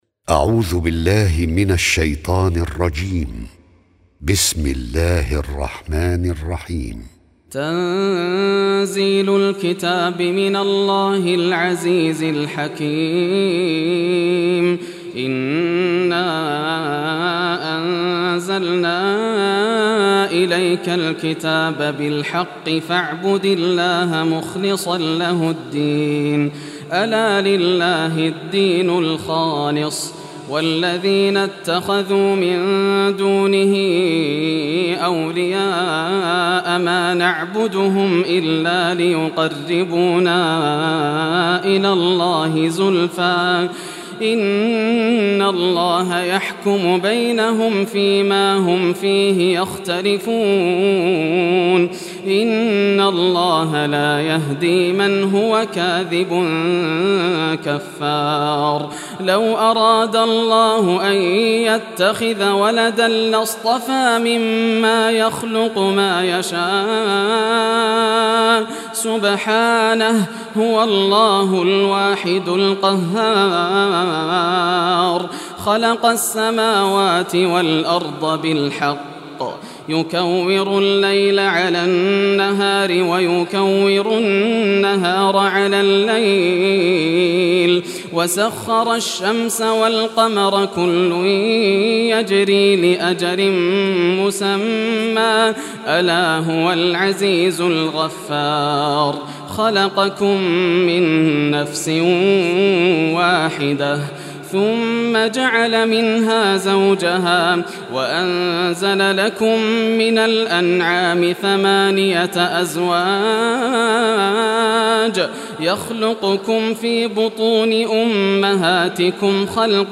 Surah Az-Zumar Recitation by Yasser al Dosari
Surah Az-Zumar, listen or play online mp3 tilawat / recitation in Arabic in the beautiful voice of Sheikh Yasser al Dosari.